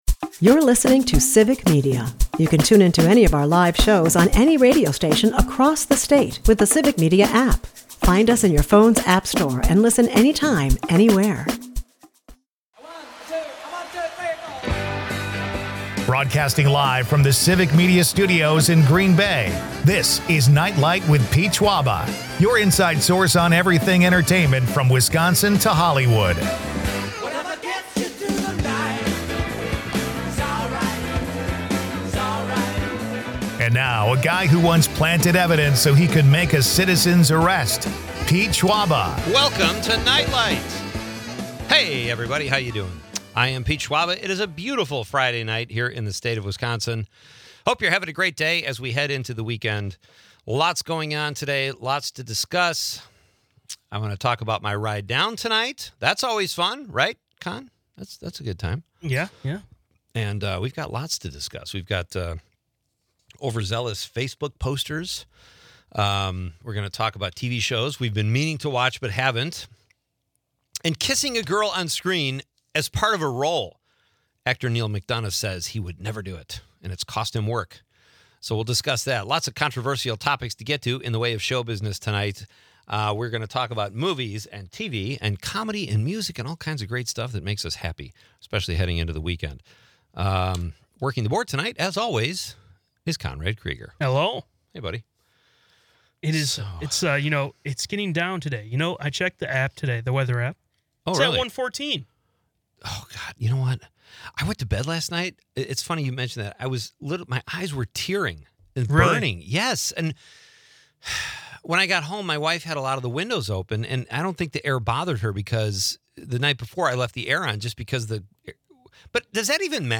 Broadcasting from Green Bay